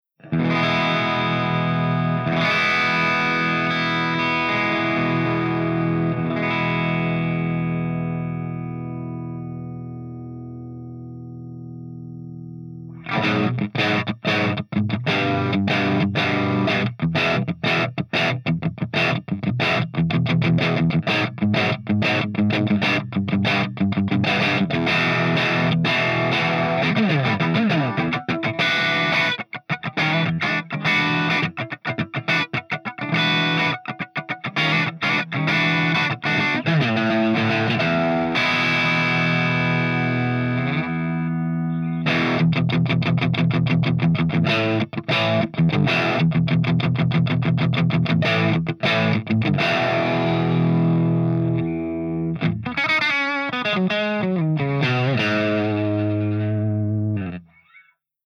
120_MARSHALLJCM800_CH2CRUNCH_GB_SC